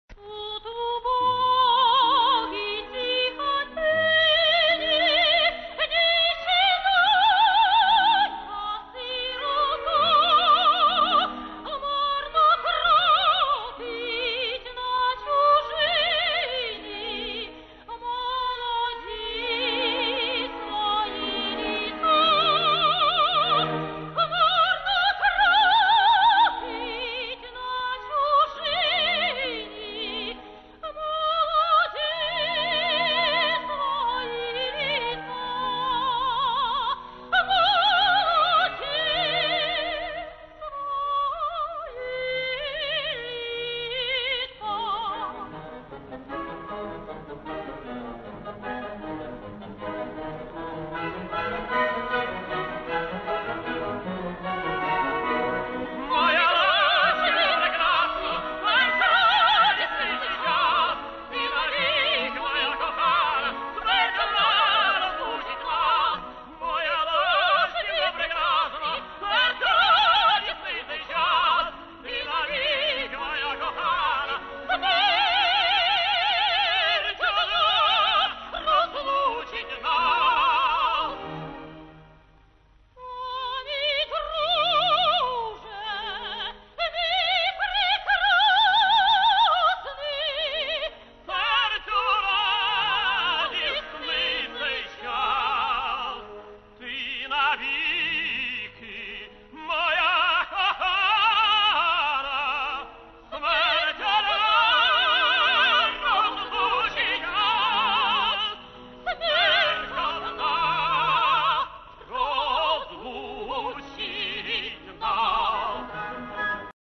Дует Оксани та Андрія
запис 1987 року